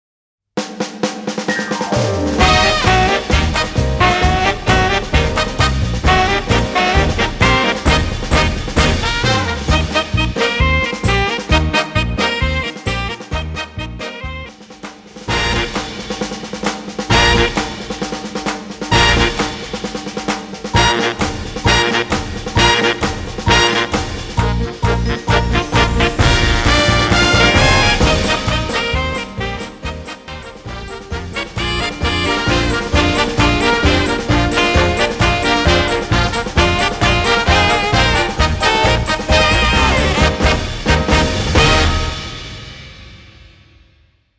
難易度 分類 並足132 時間 ２分２８秒
編成内容 大太鼓、中太鼓、小太鼓、シンバル、トリオ 作成No２５２